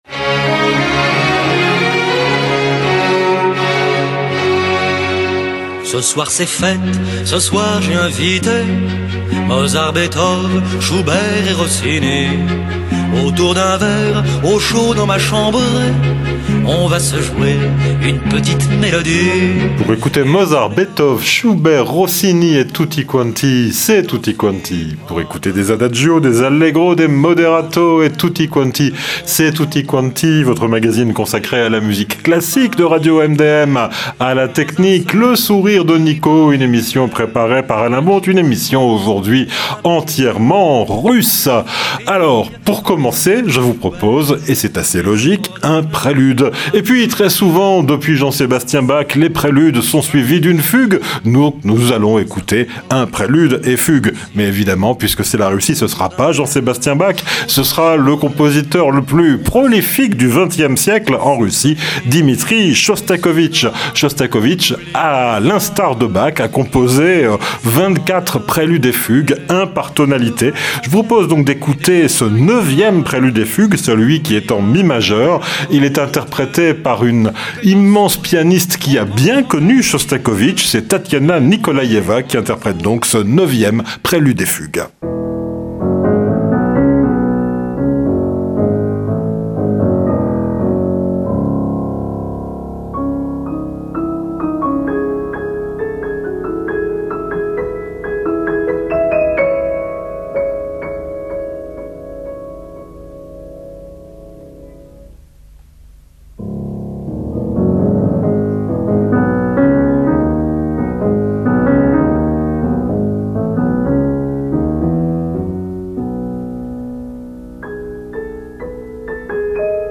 La chanson et le rock français d’aujourd’hui, les artistes locaux et les musiques du monde, dans l’esprit du Festival de Luxey.